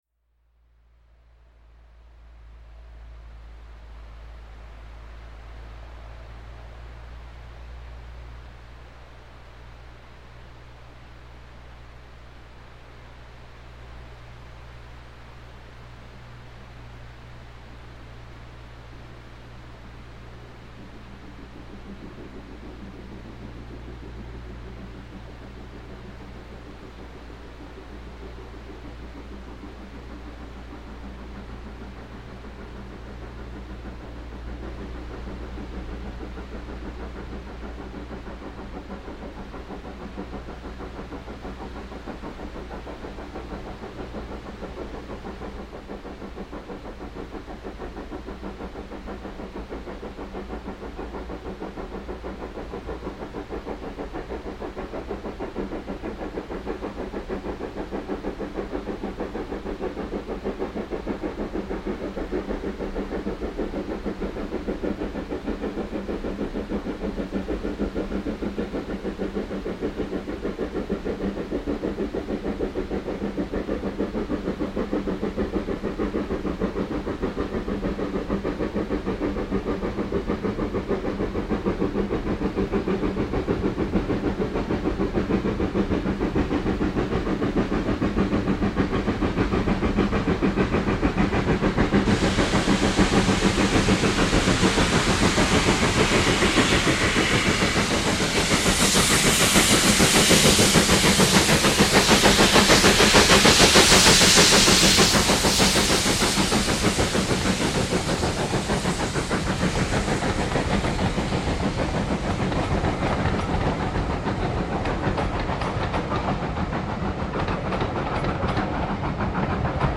62005 (ex LNER K1) mit Zug nach Pickering, aufgenommen an der inzwischen für Sound üblichen Stelle überm Wasserfall Thomason Foss bei Water Ark vom südwestlichen Wiesenhang, um 11:00h am 04.08.2000.   Hier anhören: